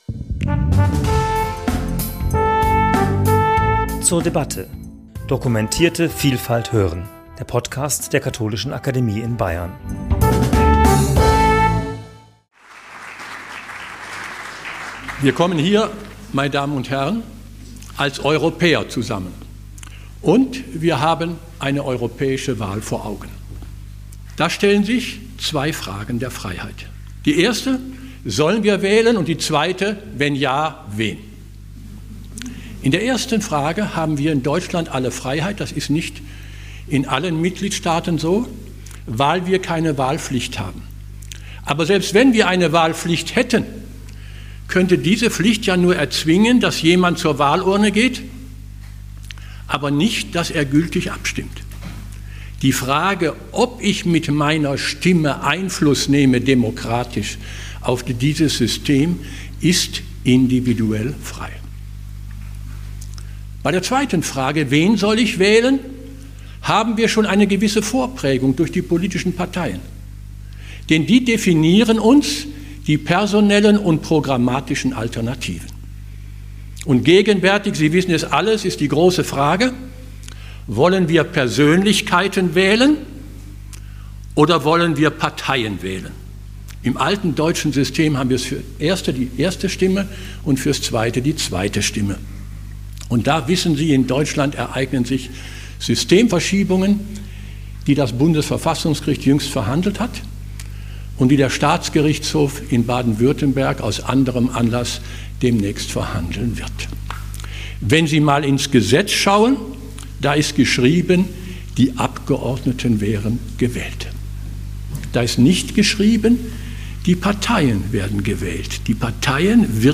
Paul Kirchhof, Richter des Bundesverfassungsgerichts a.D., referiert zur Europawahl 2024 bei einer öffentlichen Veranstaltung am 10.5.2024 in der Katholischen Akademie in Bayern.